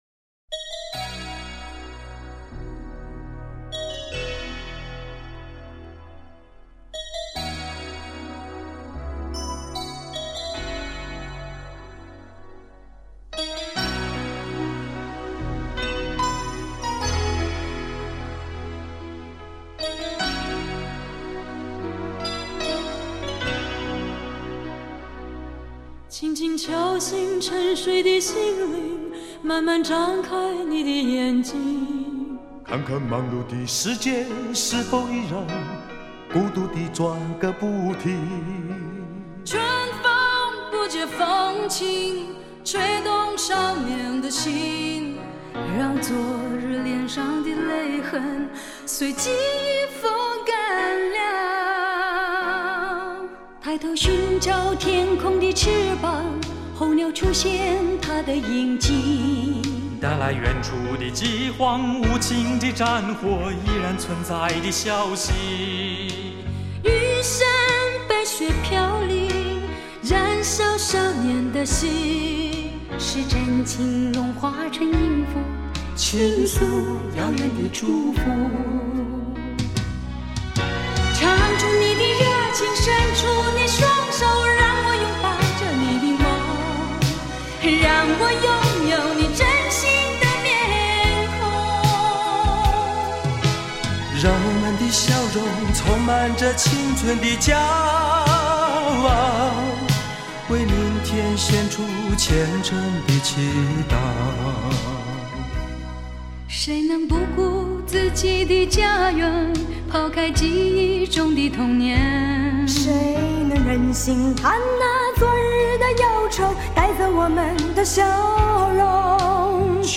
60位港台明星大合唱